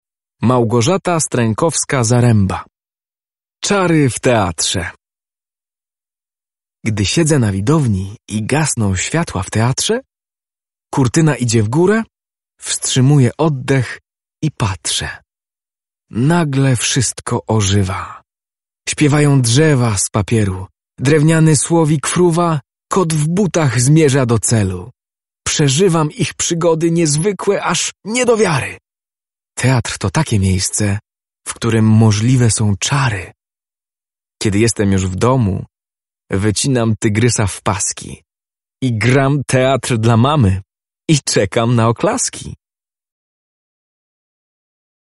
Wiersz
14opowiadanieczarywteatrze.mp3